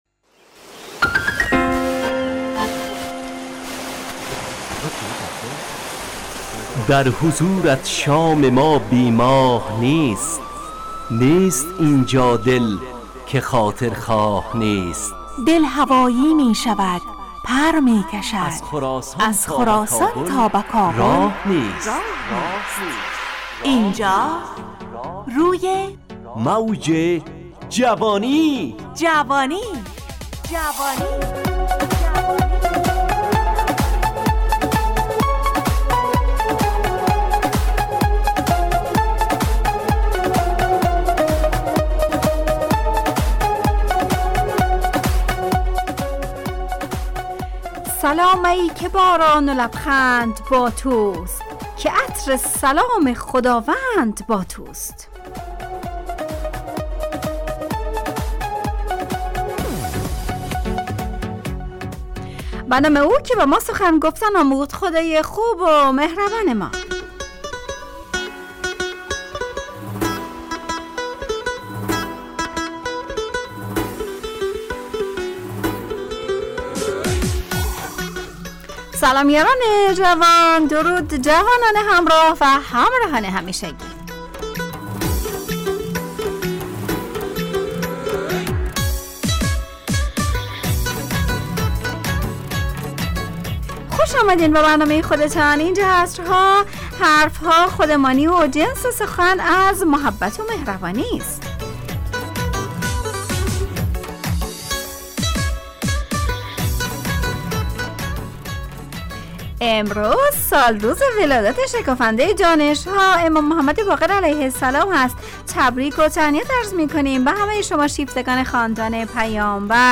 روی موج جوانی، برنامه شادو عصرانه رادیودری.
همراه با ترانه و موسیقی مدت برنامه 55 دقیقه .